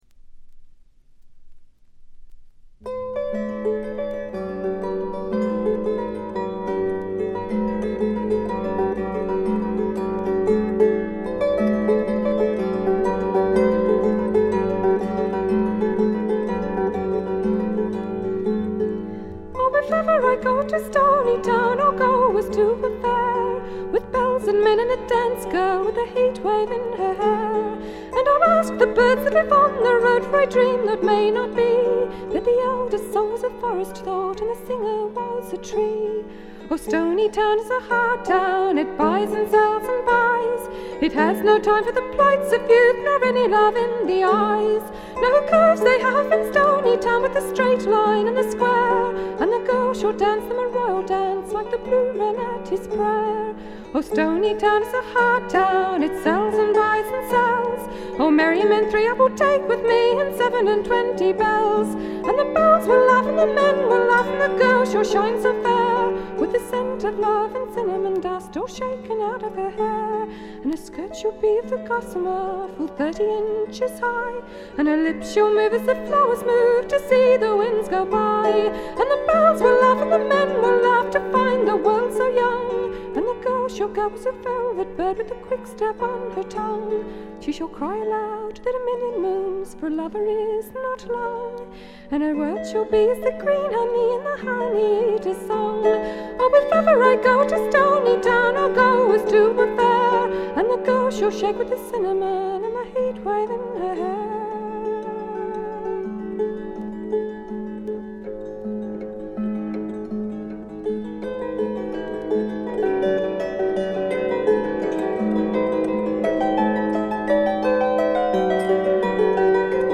ところどころで軽微なチリプチ。
トラディショナル・ソングとトラッド風味の自作曲を、この上なく美しく演奏しています。
試聴曲は現品からの取り込み音源です。
vocal, harp, banjo
viola.